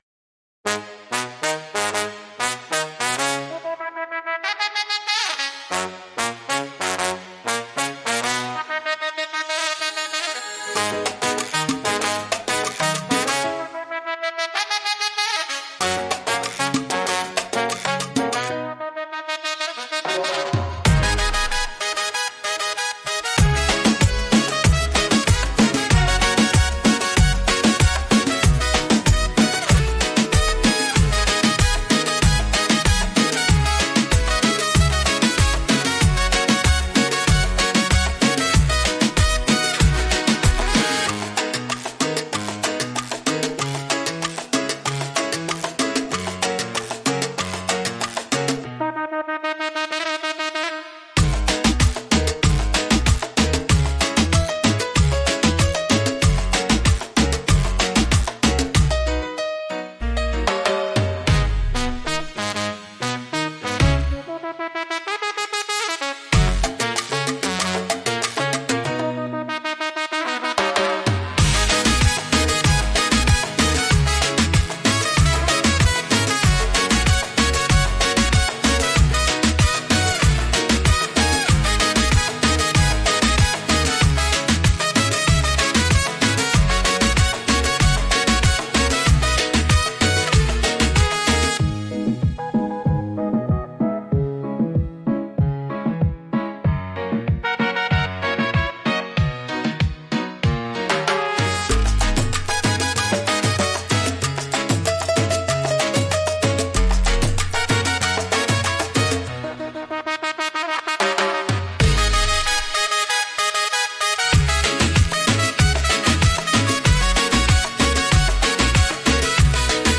Beat Reggaeton Instrumental
Acapella e Cori Reggaeton Inclusi
G#m